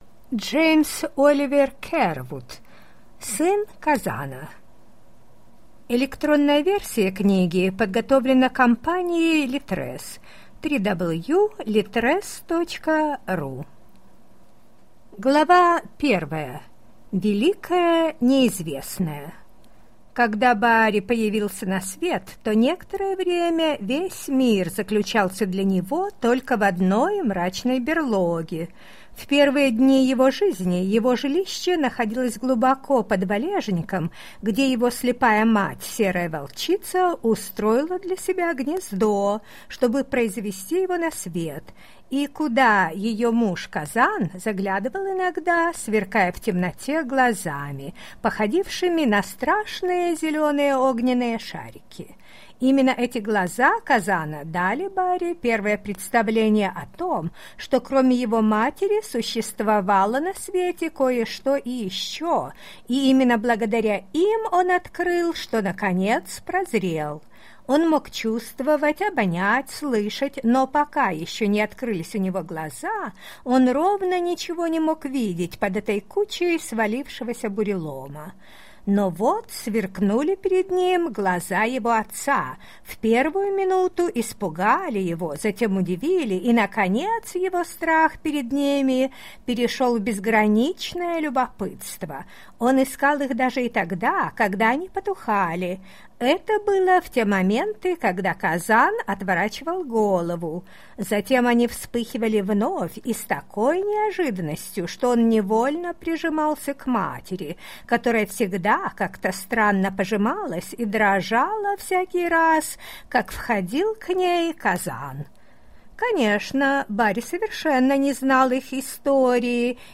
Аудиокнига Сын Казана | Библиотека аудиокниг